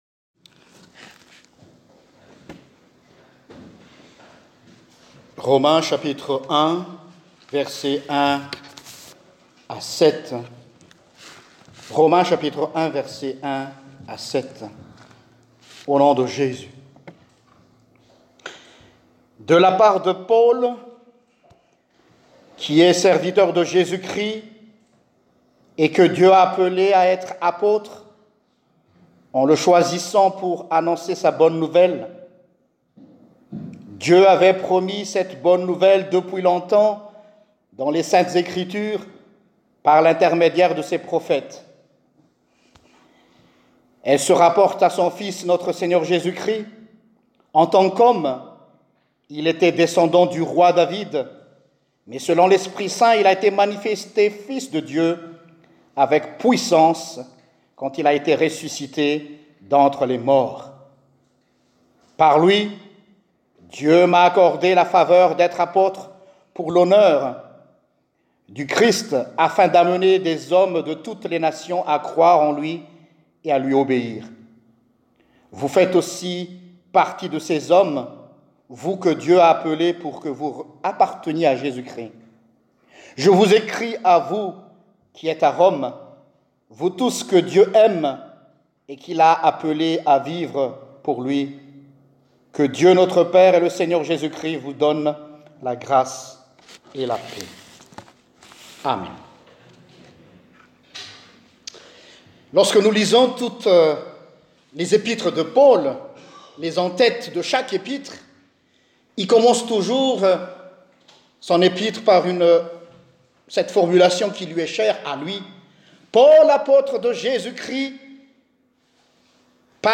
DOULOS (Prédication du 22 Décembre 2019)